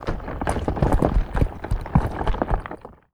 rock_smashable_falling_debris_01.wav